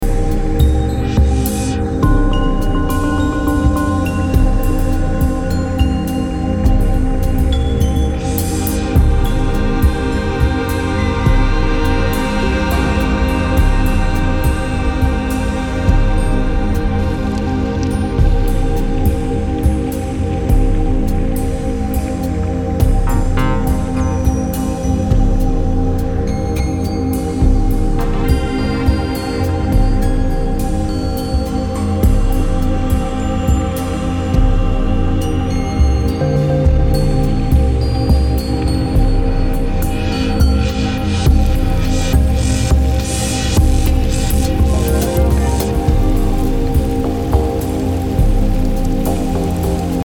Ambient, Drone >